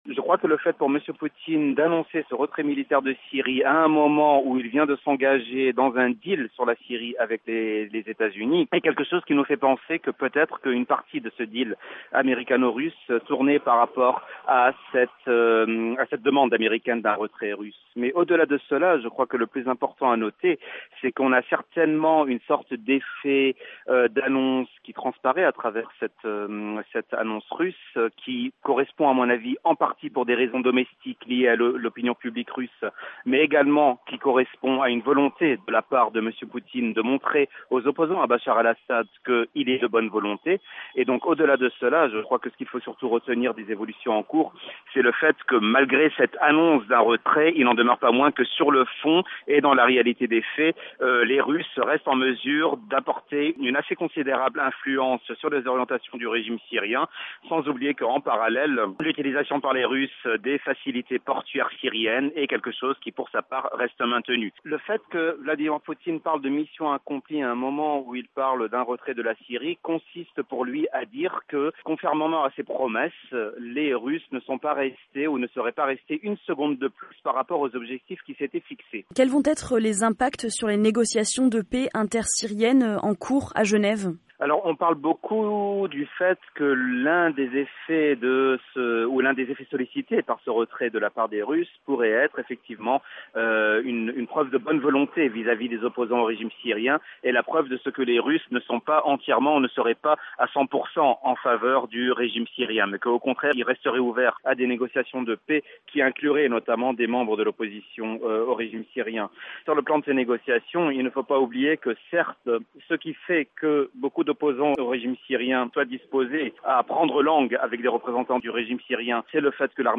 docteur en Sciences Politique et spécialiste du Moyen-Orient a été joint par VOA Afrique